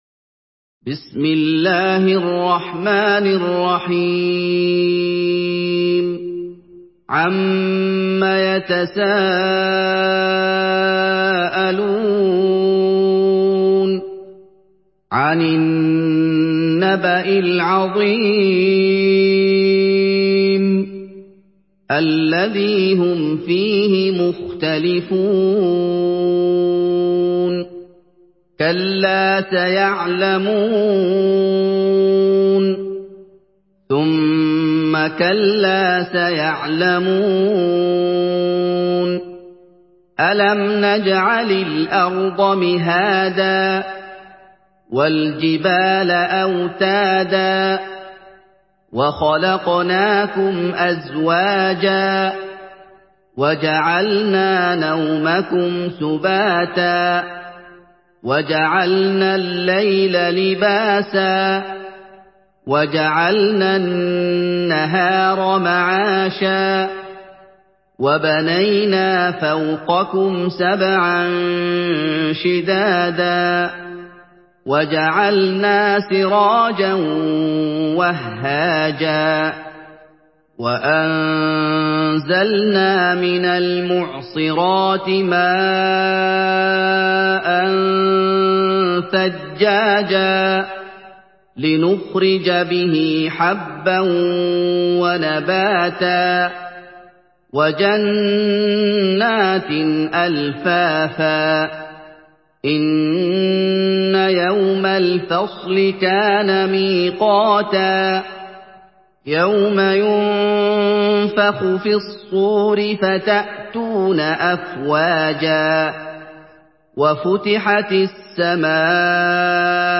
Surah আন-নাবা MP3 by Muhammad Ayoub in Hafs An Asim narration.